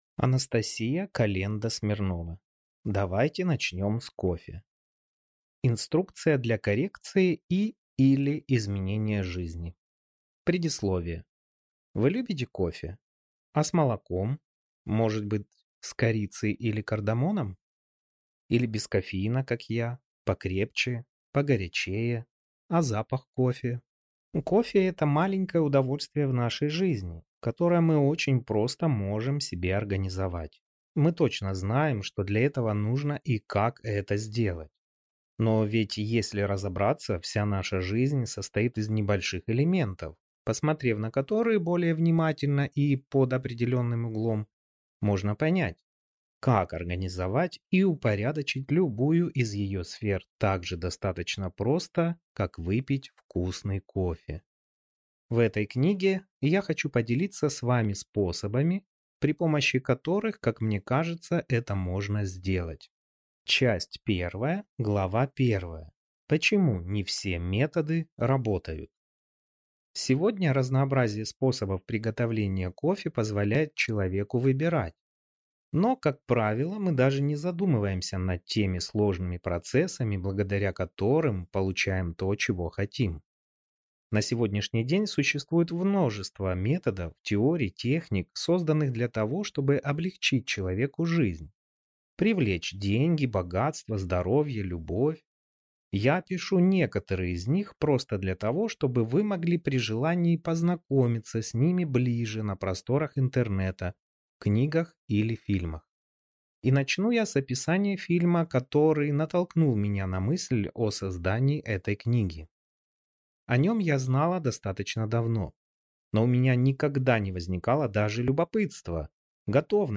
Аудиокнига Давайте начнем с кофе! Инструкция для коррекции и/или изменения жизни | Библиотека аудиокниг